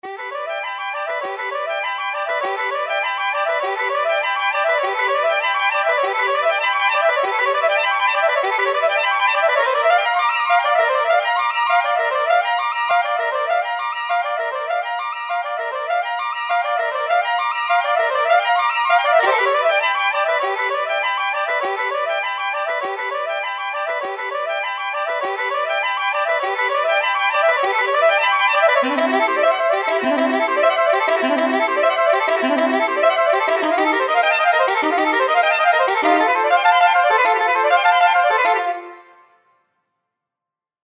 Das Saxophon bietet innerhalb des Sustain-Instruments auch Halb- und Ganztonschritt-Triller, temposynchron oder mit variabler Geschwindigkeit.
Kombiniert man die Triller-Geschwindigkeit mit der Dynamik, indem man beide Parameter dem selben Modulator (z.B. dem Modulationsrad) zuweist und schaltet zudem noch ein temposynchrones 1/16tel -Arpeggio ein, so entstehen aberwitzige Klangmuster:
Ein leibhaftiger Saxofonist dürfte Schwierigkeiten haben, das nachzuspielen.